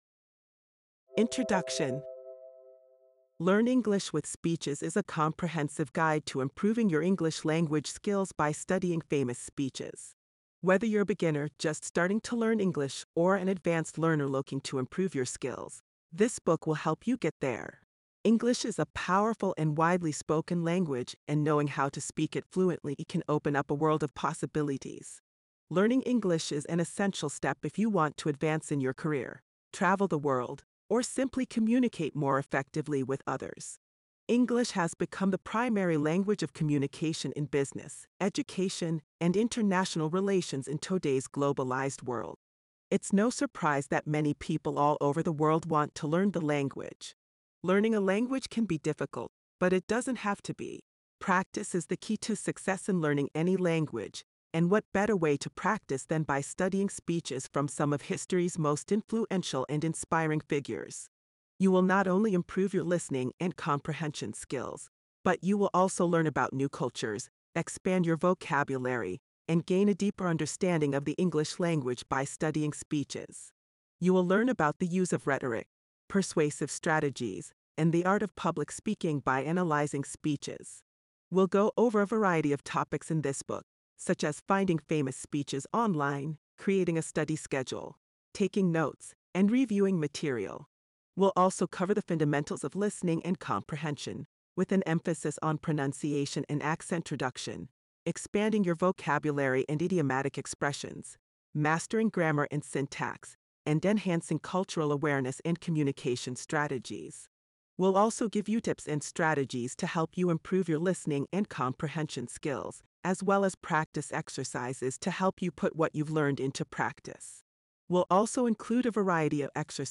• Professional narration: High-quality, engaging voiceover by a native English speaker to ensure accurate pronunciation
Audiobook Preview